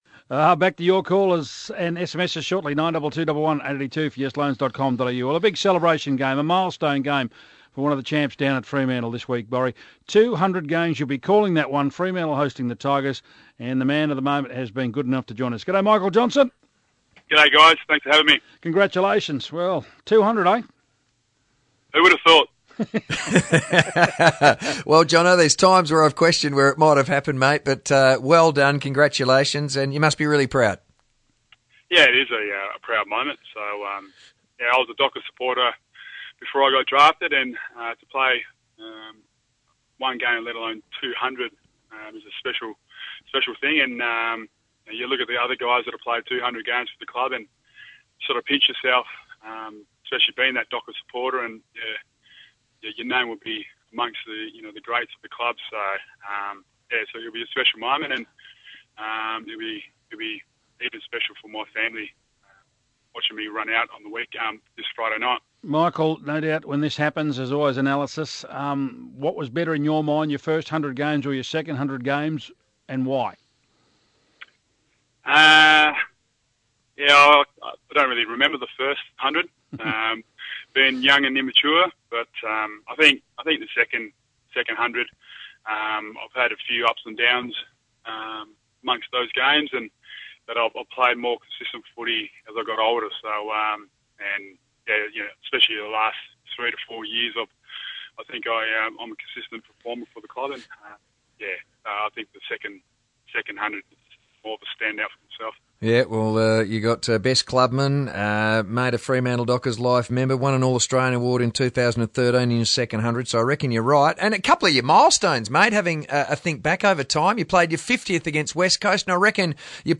Michael Johnson speaks to Sports Today ahead of his 200th game